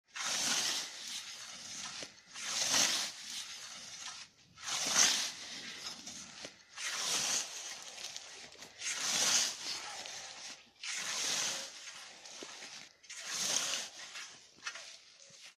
На этой странице собраны звуки косы — от металлического звона лезвия до ритмичного шелеста скошенной травы.
Шум косы при кошении травы